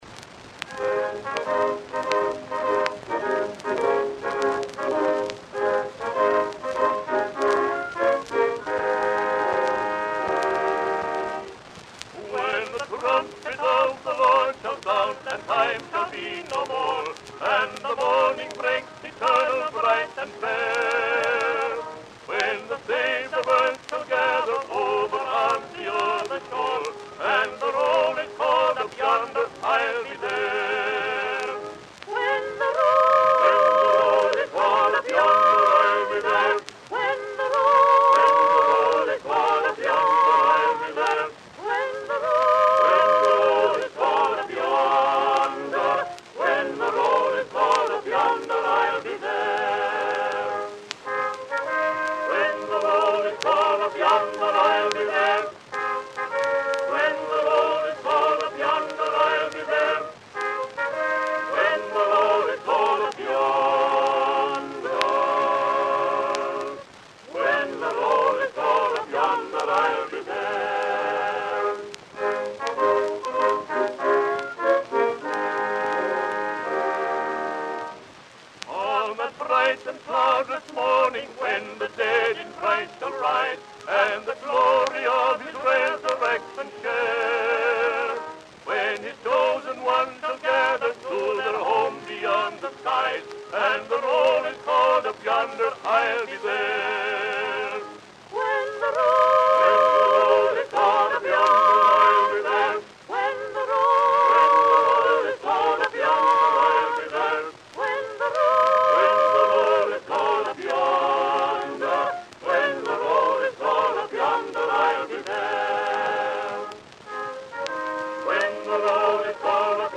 On label: tenor, baritone and mixed quartet with orchestra.
Hymns.
Sacred vocal ensembles.
Sacred duets with orchestra.
Gospel music.
1 sound disc : analog, 80 rpm ; 10 in.